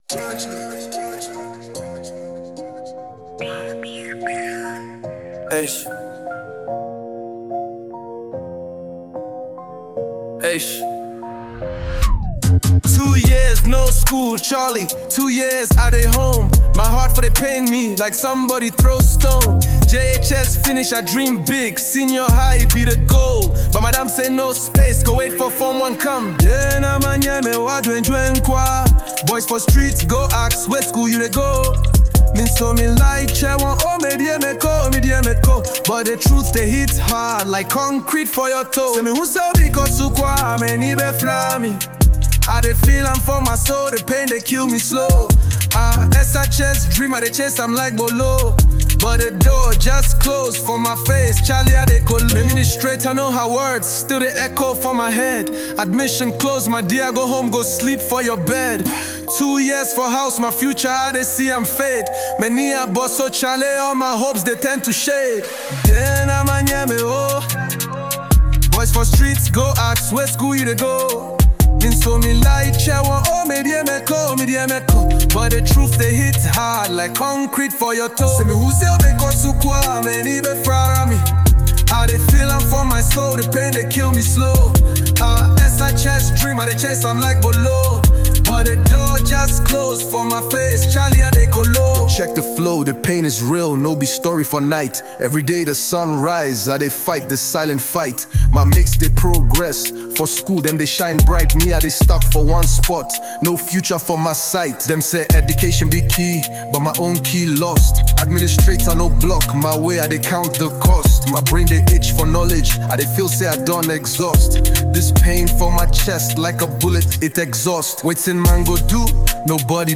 delivered with a mix of raw emotion and compelling flow